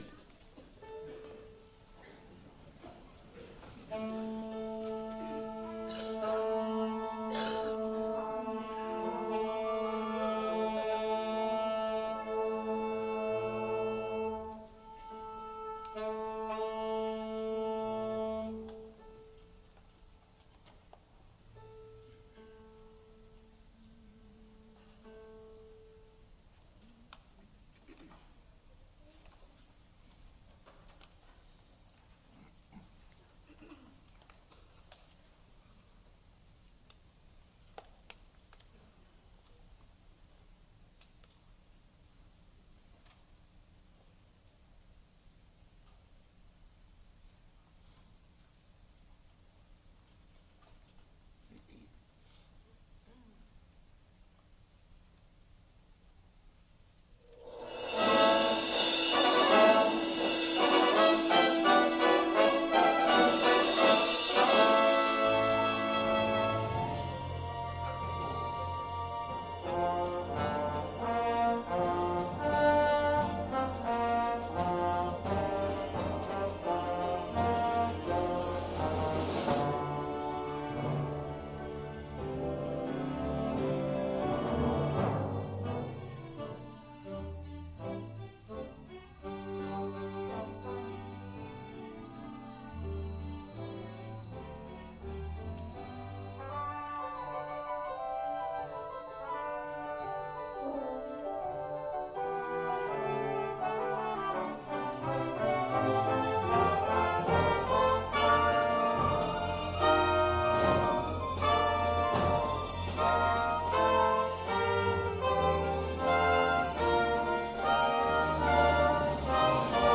12.17.2017 Christmas Cantata